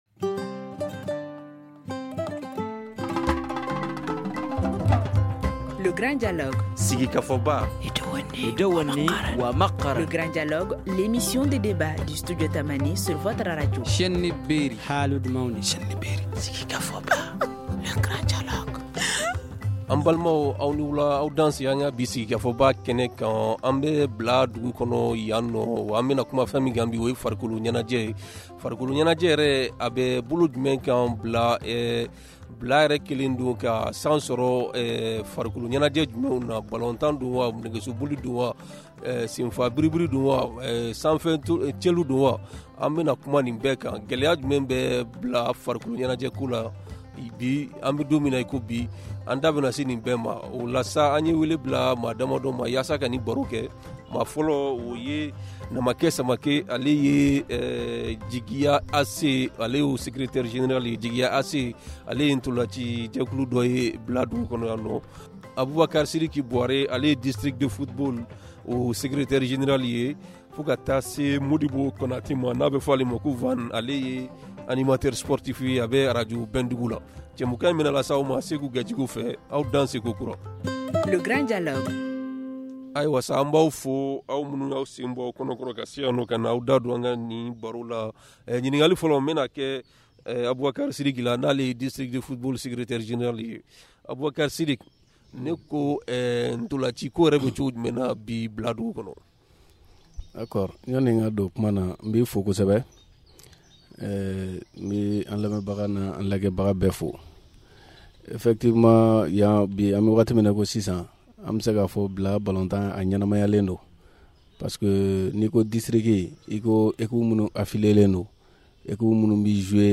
Studio Tamani pose le débat avec nos invités :